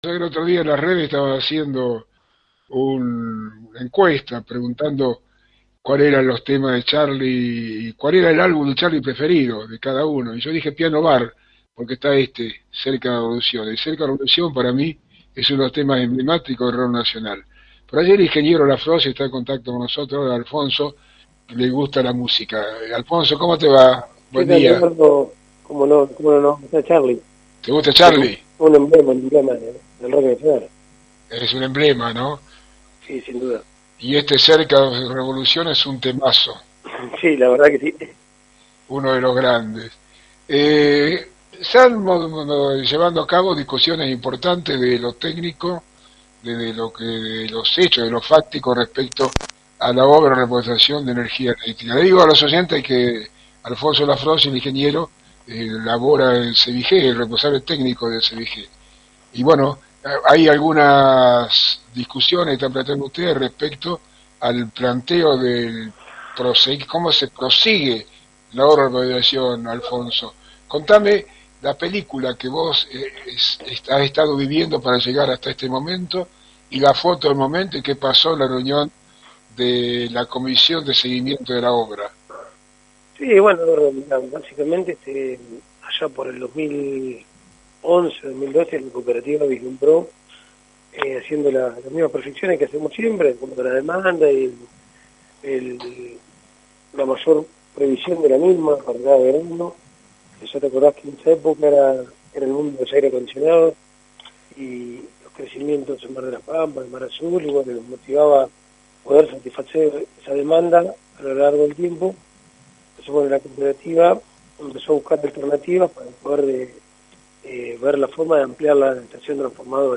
Al final de la nota el audio completo de la entrevista